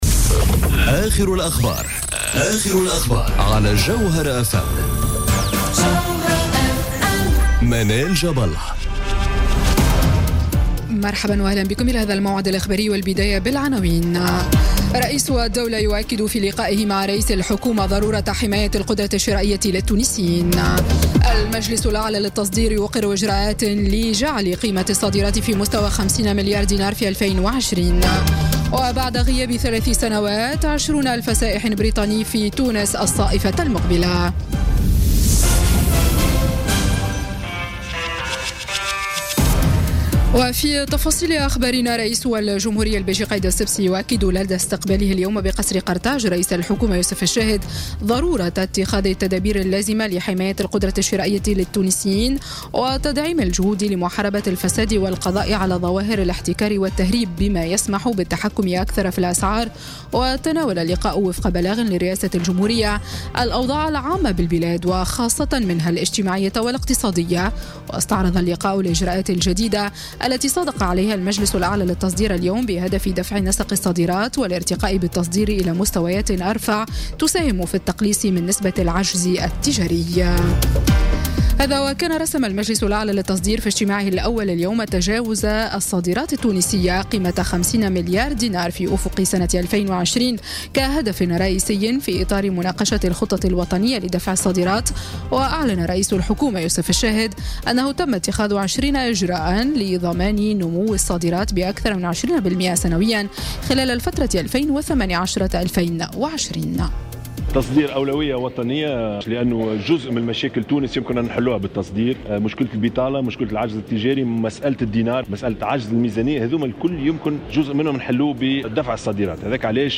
نشرة أخبار السابعة مساءً ليوم الخميس 4 جانفي 2018